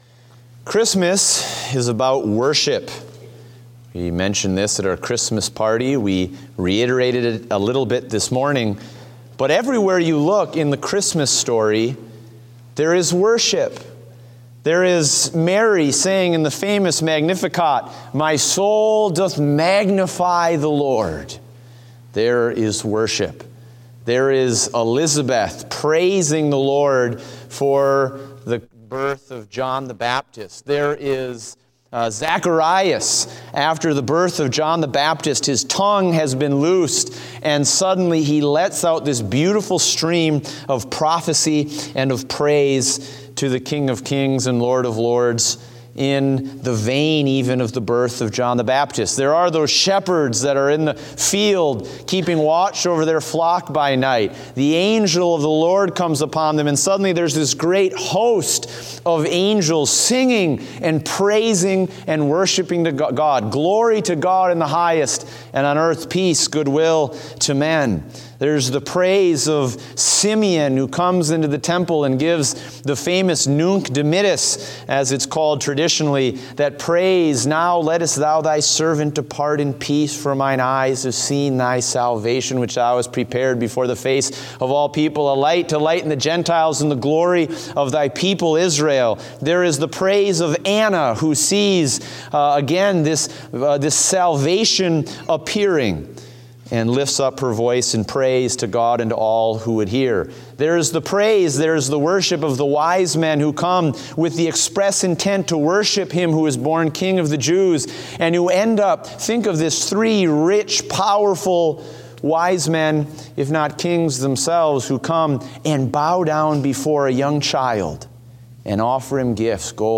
Date: December 18, 2016 (Evening Service)